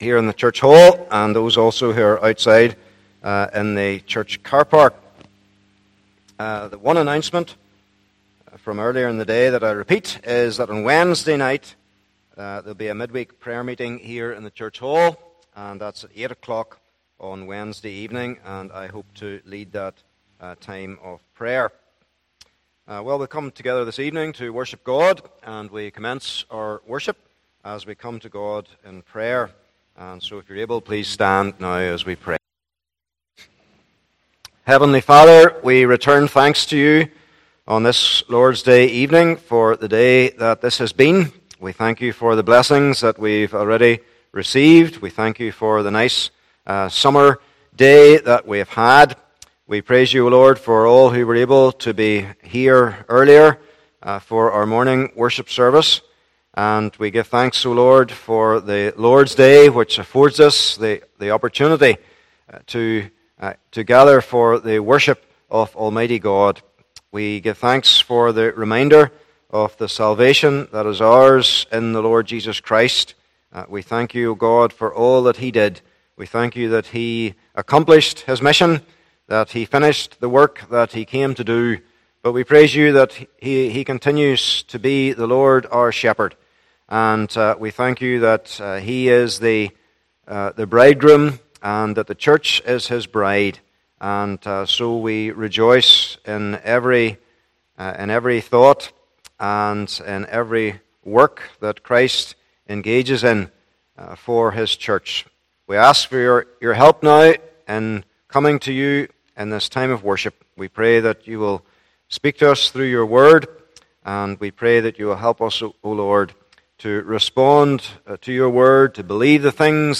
8 & 9 Service Type: Evening Service Bible Text